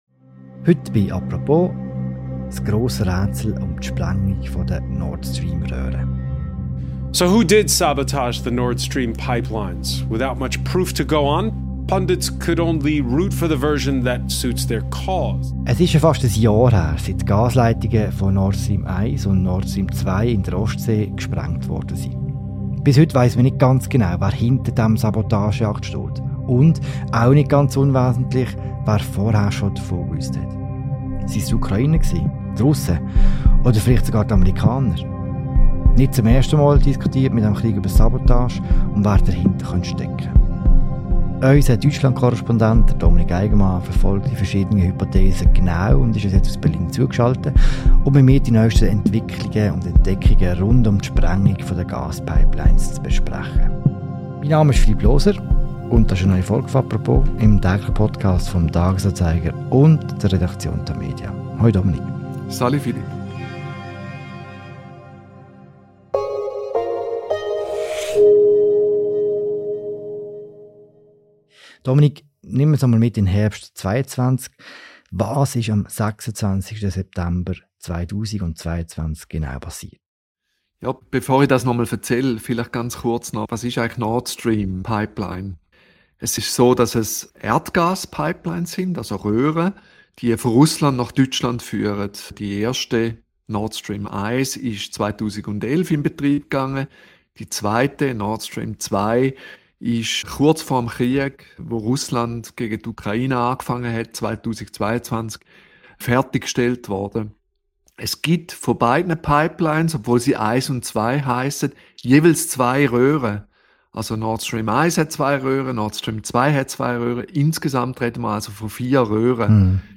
ist in dieser Sendung aus Berlin zu geschalten